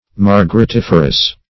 Search Result for " margaritiferous" : The Collaborative International Dictionary of English v.0.48: Margaritiferous \Mar`ga*ri*tif"er*ous\, a. [L. margaritifer; margarita pearl + ferre to bear: cf. F. margaritif[`e]re.] Producing pearls.